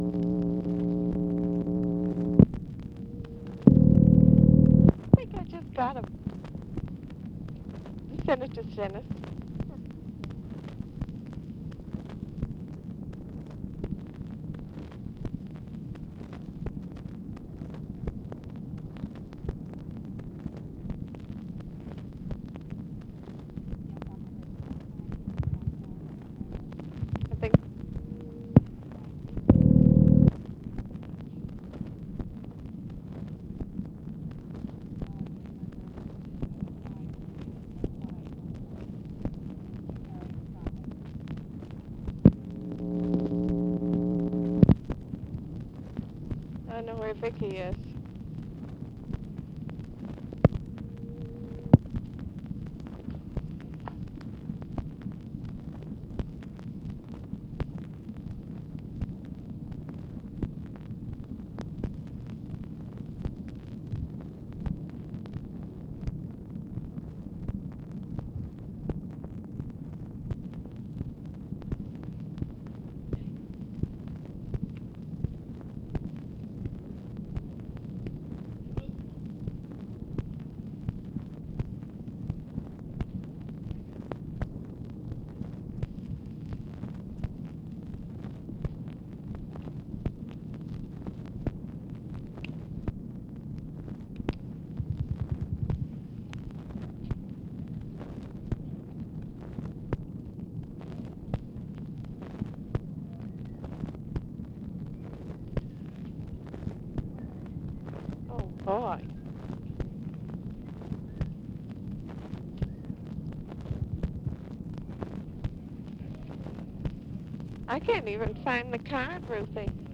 OFFICE CONVERSATION, August 6, 1965
Secret White House Tapes | Lyndon B. Johnson Presidency